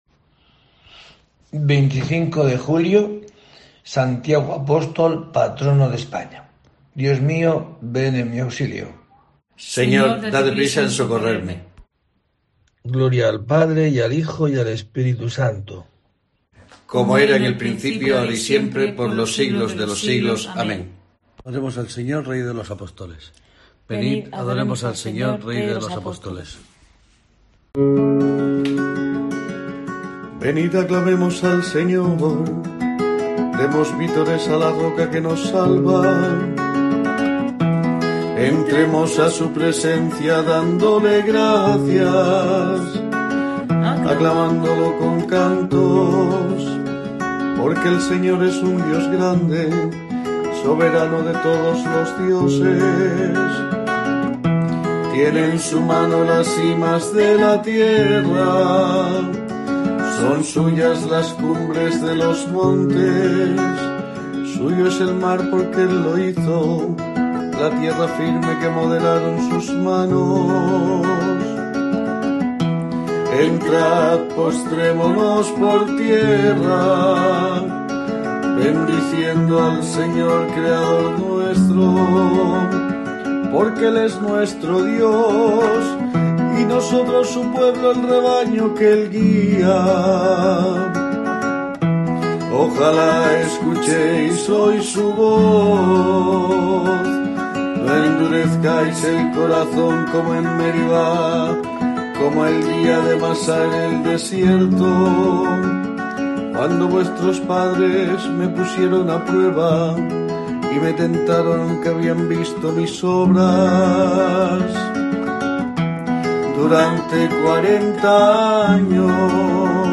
25 de julio: COPE te trae el rezo diario de los Laudes para acompañarte